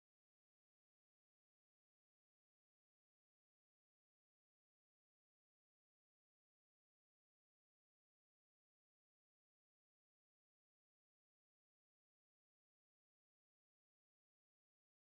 Kindertänze: Rosen auf mein Hütchen
Tonart: C-Dur
Taktart: 3/4
Tonumfang: große None
Anmerkung: - implizite Taktangabe - impliziter Taktwechsel von Takt 2 zu 3 (3/4 zu 2/4) und von Takt 3 zu 4 (2/4 zu 3/4)